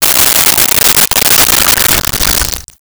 Pour Liquid Into Styrofoam Cup 01
Pour Liquid into Styrofoam Cup 01.wav